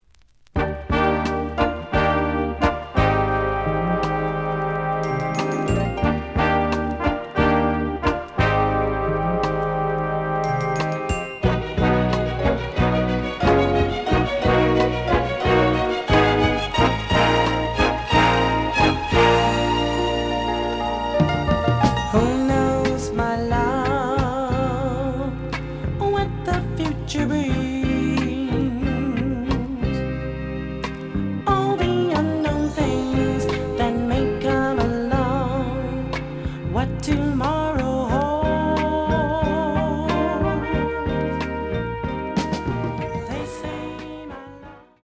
試聴は実際のレコードから録音しています。
●Genre: Disco
●Record Grading: EX- (盤に若干の歪み。多少の傷はあるが、おおむね良好。)